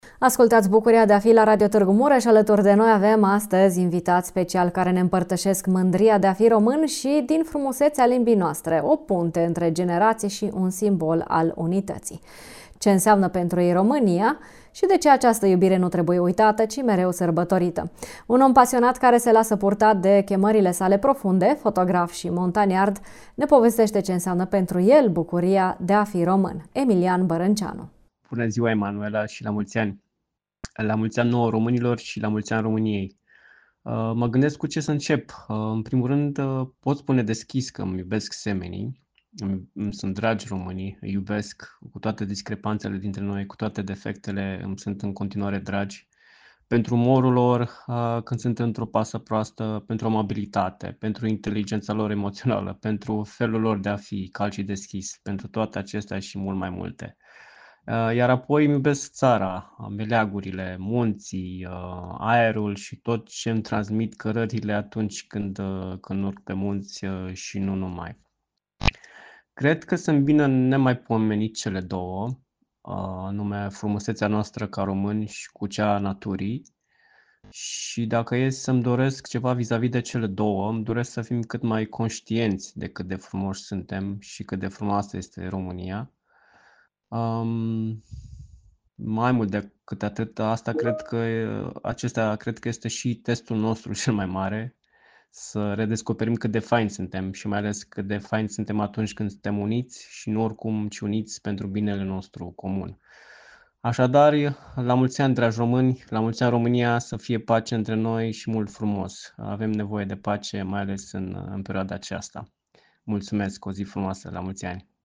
Un om pasionat care se lasă purtat de toate chemările sale profunde, fotograf și montaniard, ne arată bucuria sa de a fi român.